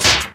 EIGHTYCLAP.wav